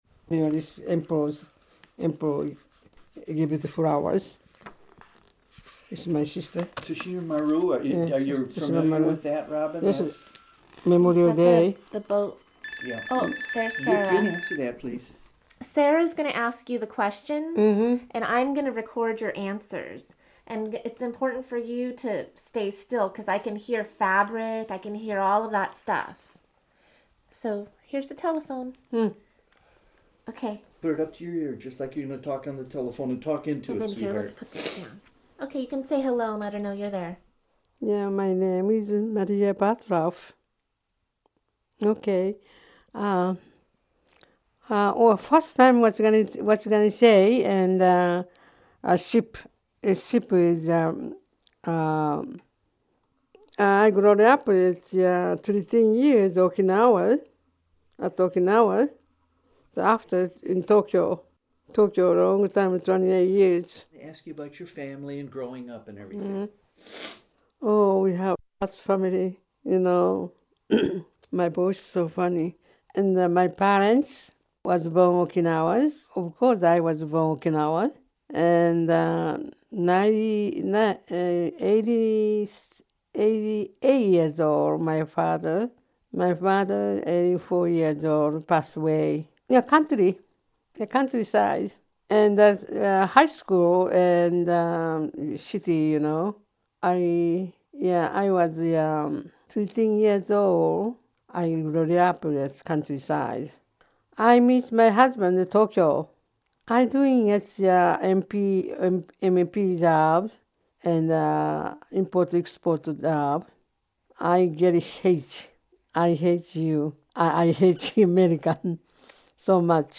Interview
via phone Military Brides